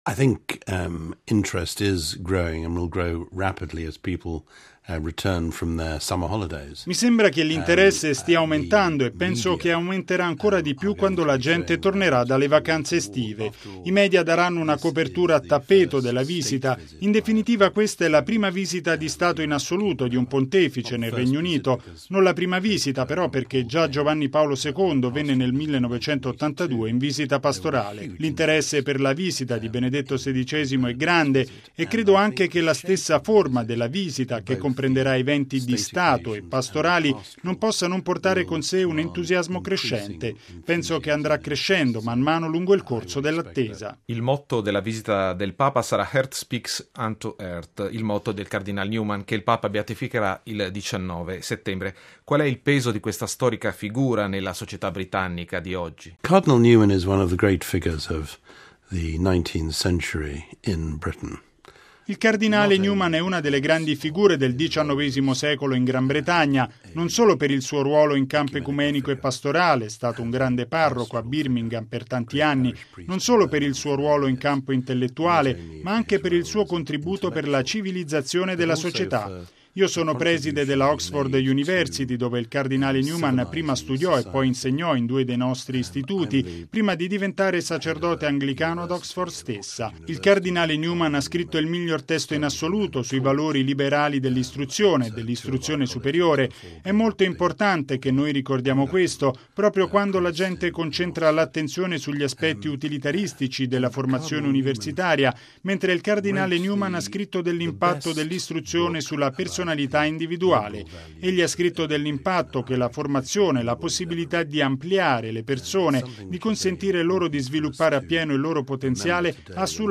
◊   Ad un mese e mezzo dall’inizio del viaggio apostolico di Benedetto XVI nel Regno Unito, il 16 settembre prossimo, cresce l’aspettativa nella comunità cattolica britannica e non solo. A sottolinearlo è Lord Christopher Patten, incaricato del primo ministro britannico per la visita papale nel Regno Unito, intervistato